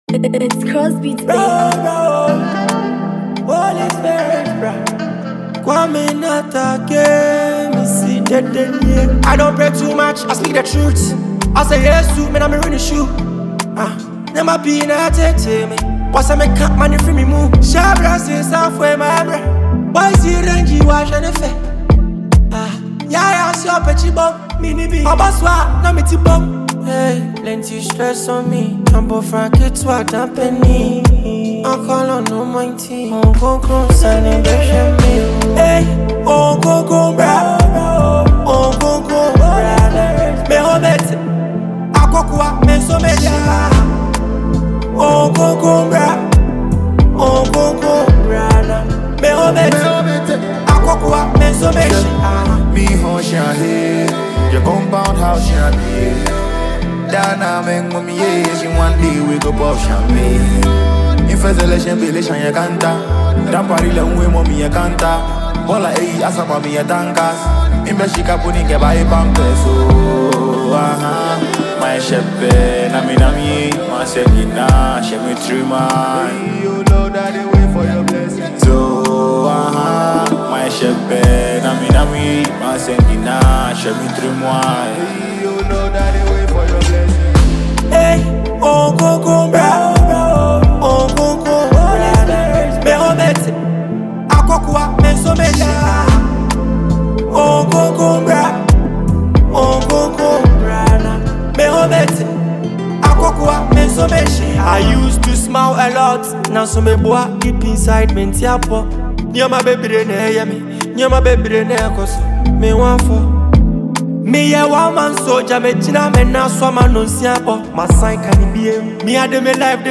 Ghana Gospel Music